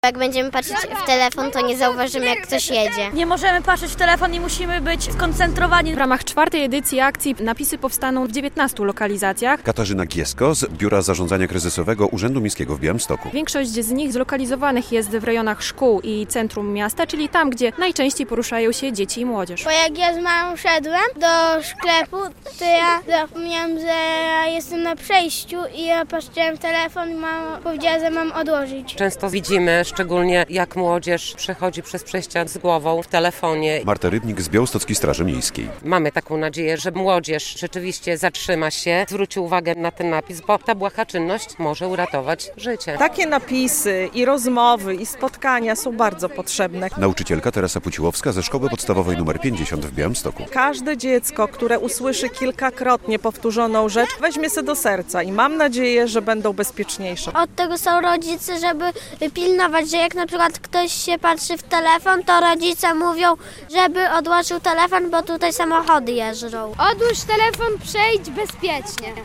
Koło kilkunastu przejść dla pieszych w Białymstoku pojawiły się nowe napisy ostrzegawcze - relacja